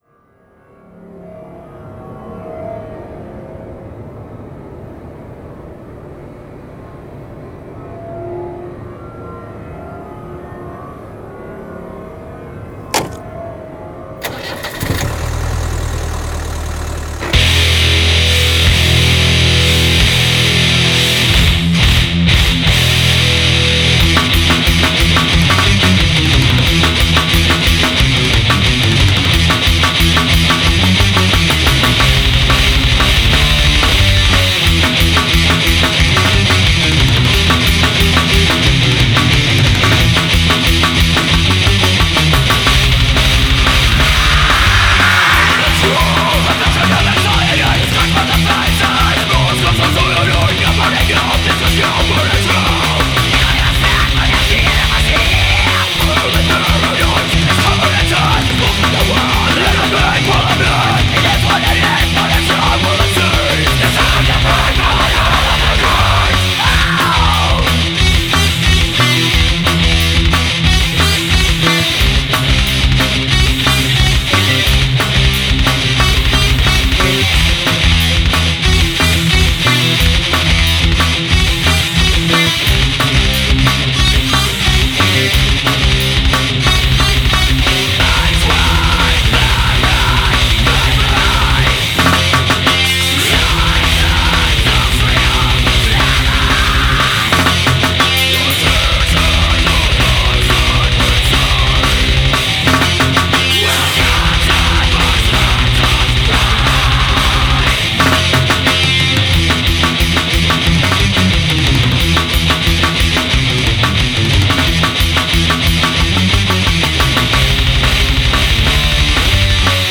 I've also attached demo's of two songs that will appear on this album as well as some imagery that we've associated ourselves with in the past.
Metal, Progressive Metal, Thrash Metal, Hard Rock